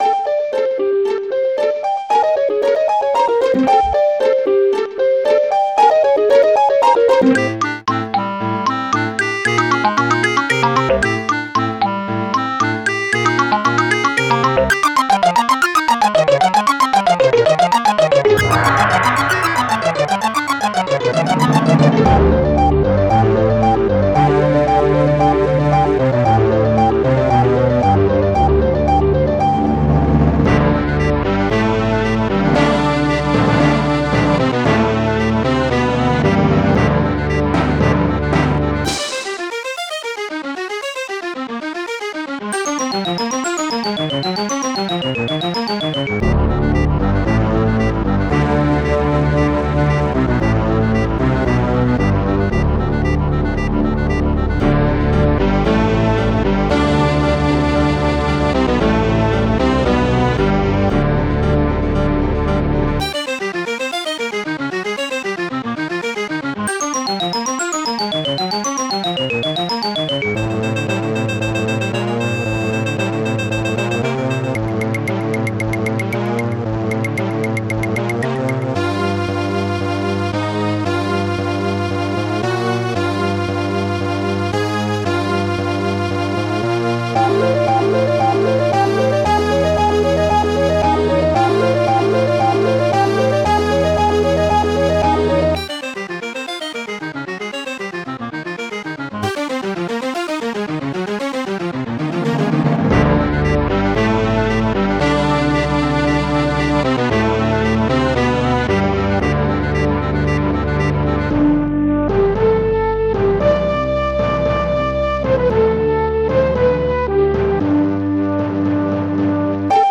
This file is an audio rip from a(n) SNES game.